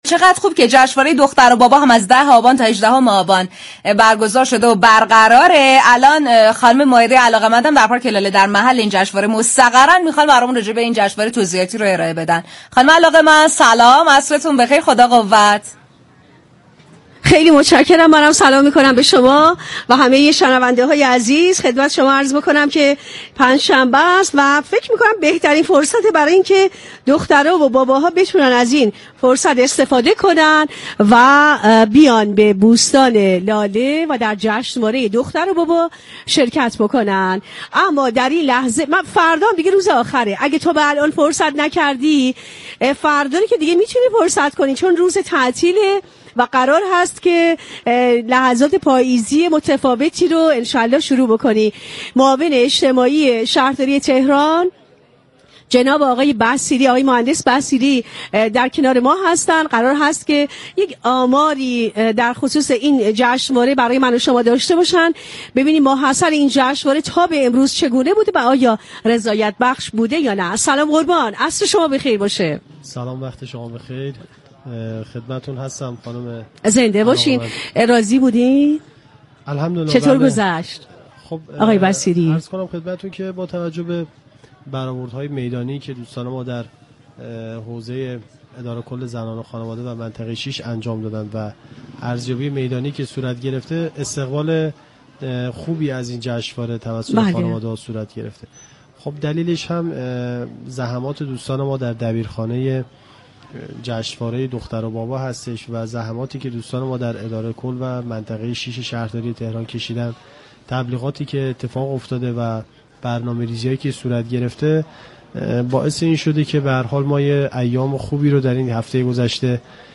بصیری در ادامه گفتگو با رادیو تهران تاكید كرد: در جشنواره «دختر و بابا» به موضوعات اساسی در خصوص دختران و باباها پرداخته شده كه در خانواده‌ها مغفول مانده و كمرنگ است .